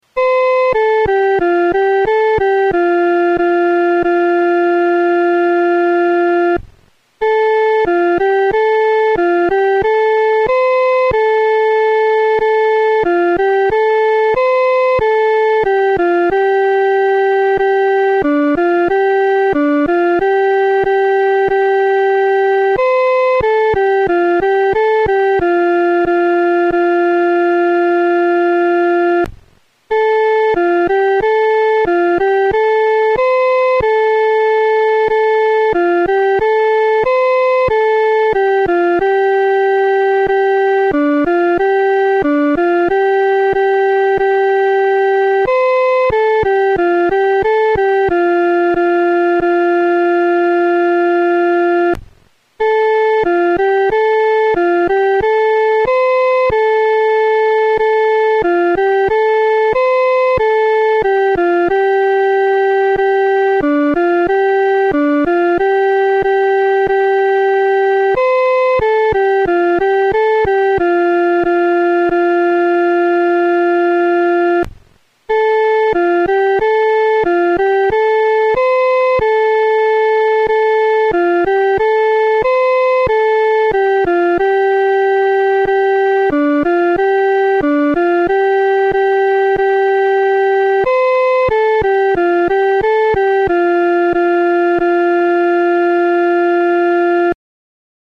伴奏
这首诗歌可用较流动的中速来弹唱。